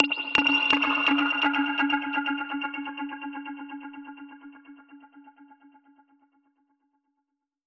Index of /musicradar/dub-percussion-samples/125bpm
DPFX_PercHit_E_125-05.wav